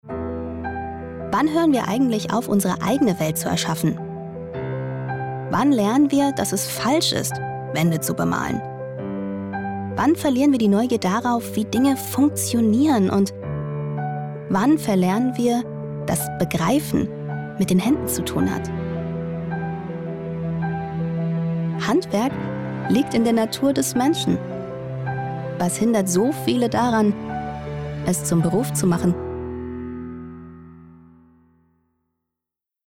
Commercial (Werbung)